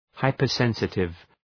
Προφορά
{,haıpər’sensıtıv}